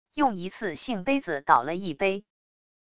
用一次性杯子倒了一杯wav音频